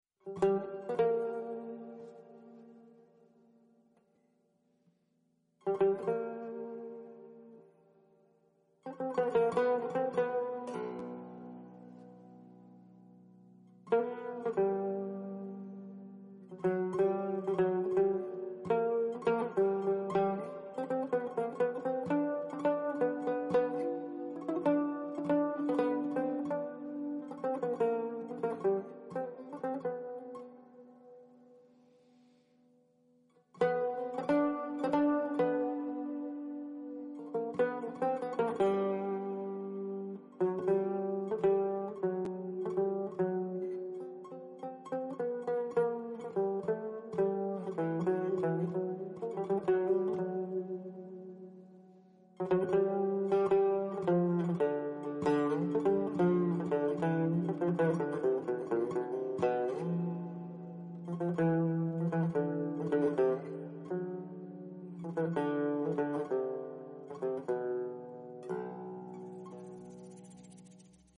Tanbur İcra Örnekleri
Tanbur Taksimi